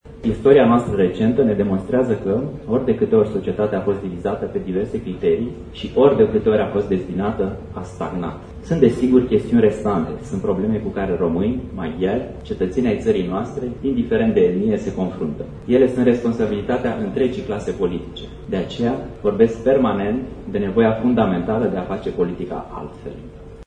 Preşedintele Klaus Iohannis a trimis un mesaj Congresului UDMR, în care a afirmă că în România trebuie făcută o altă politică, care este în interesul tuturor cetăţenilor români, indiferent de etnia lor.  Mesajul șefului statului a fost transmis de consilierul prezidențial Laurențiu Ștefan: